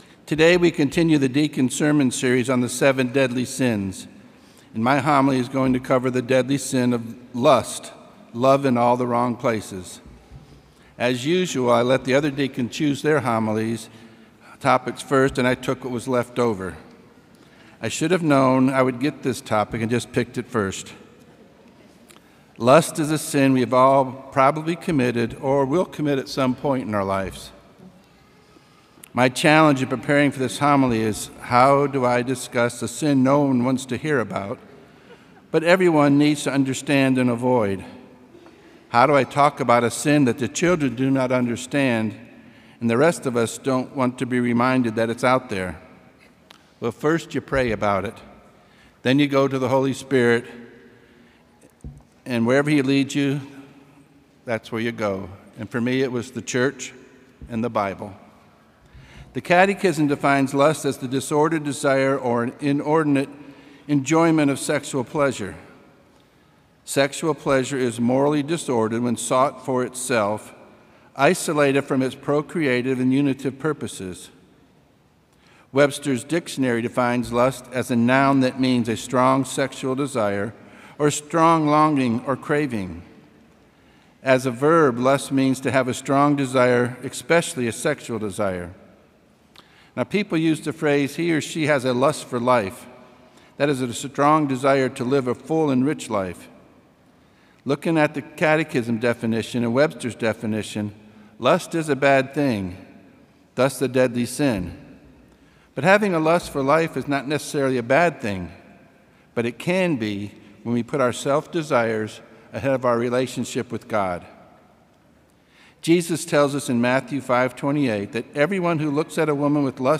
Homily
preaches the homily on the Sixth Sunday in Ordinary Time